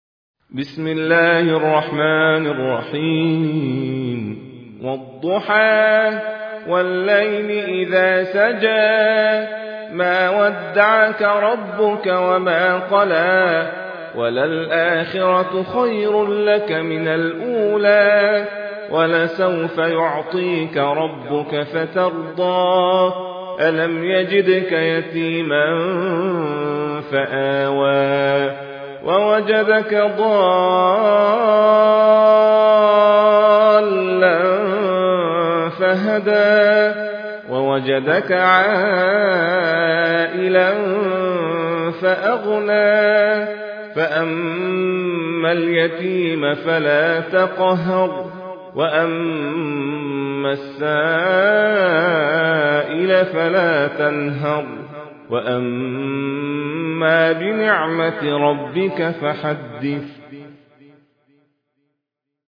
المصحف المرتل - حفص عن عاصم